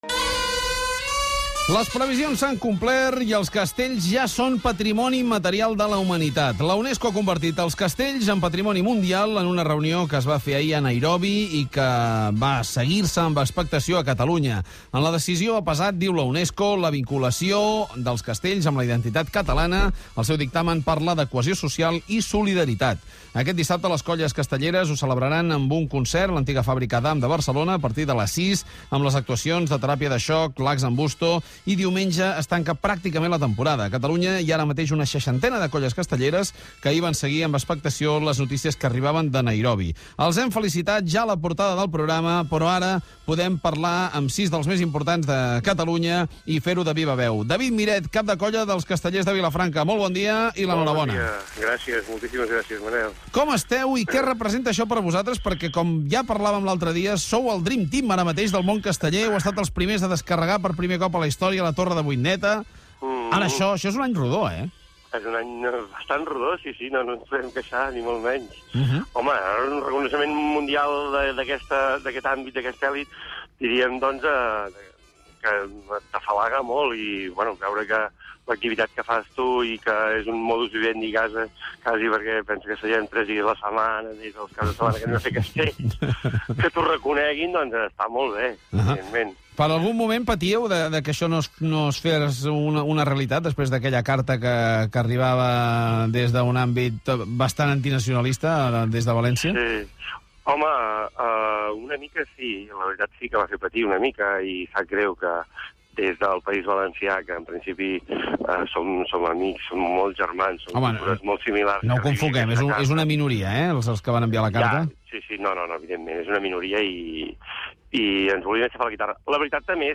Info-entreteniment
Presentador/a
Fuentes, Manel